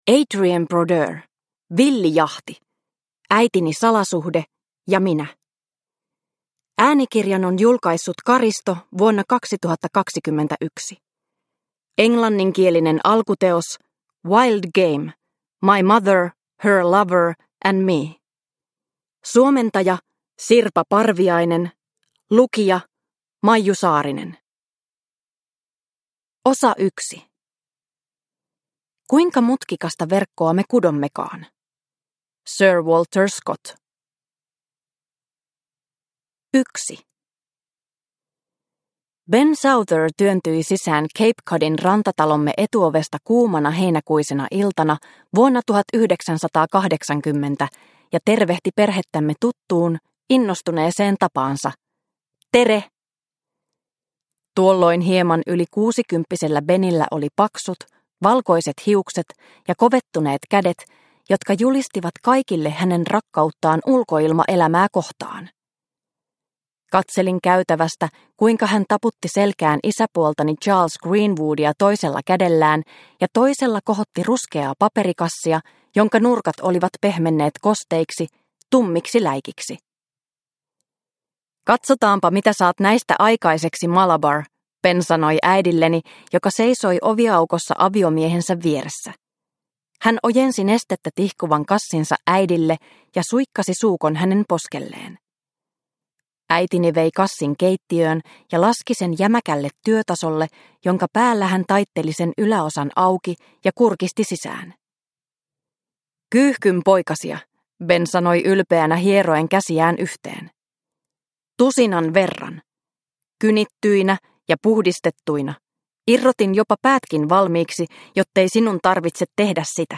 Villi jahti – Ljudbok